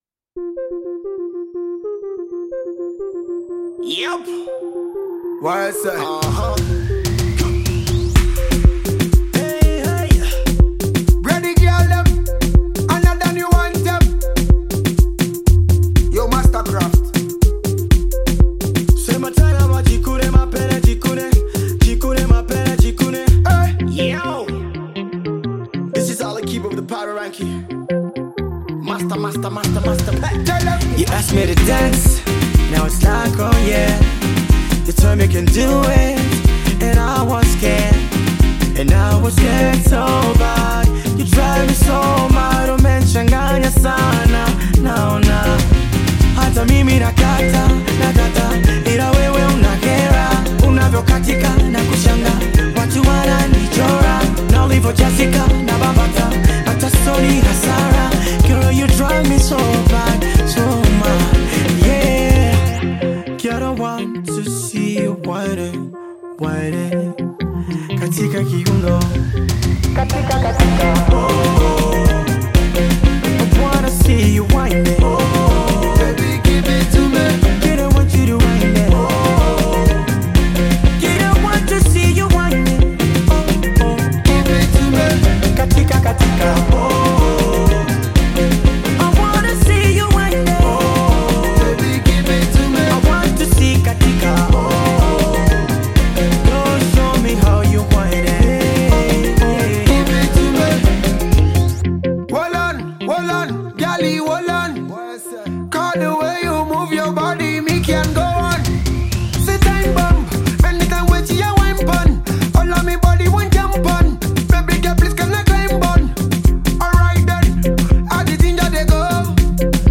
Multi-award winning African Dancehall act from Nigeria
traditional African Drums